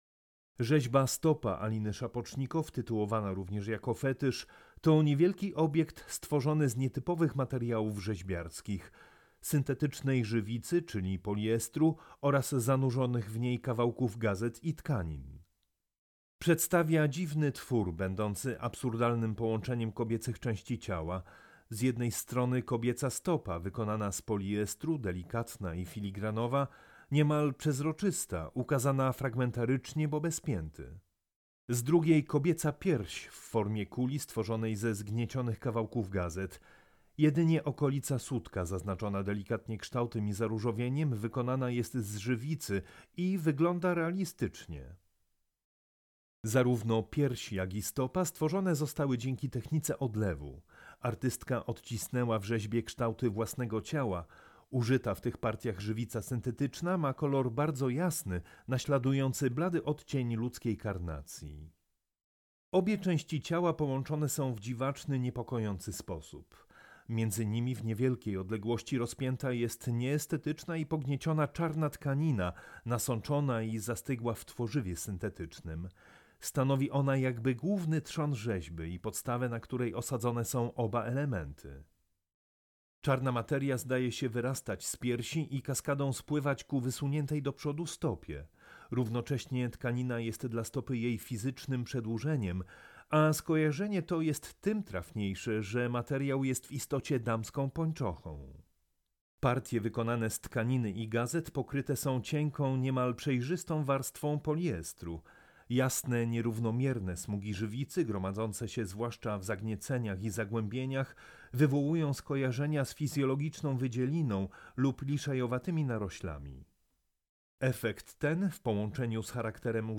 AUDIODESKRYPCJA dla osób z dysfunkcją wzroku
AUDIODESKRYPCJA-Alina-Szapocznikow-Stopa-Fetysz.mp3